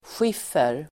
Uttal: [sj'if:er]